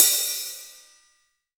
Index of /90_sSampleCDs/AKAI S6000 CD-ROM - Volume 3/Hi-Hat/12INCH_LIGHT_HI_HAT